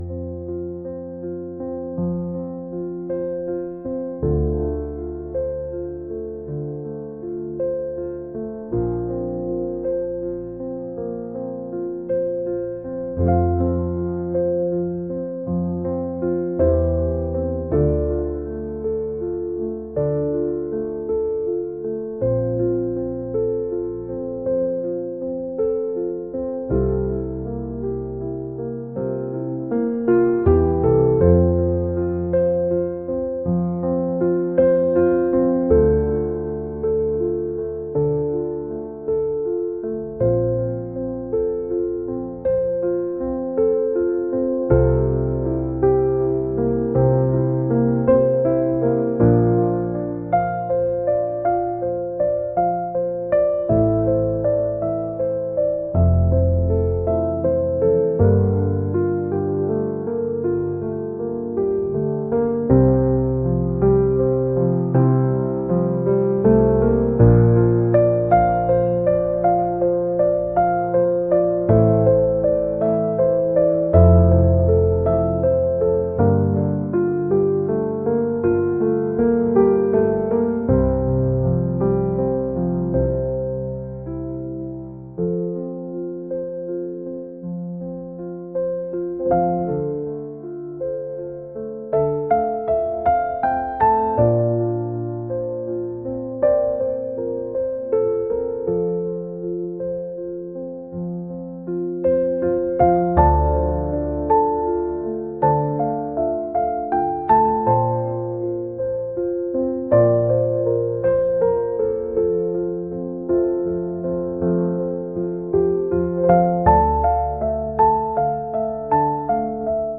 pop | ambient | cinematic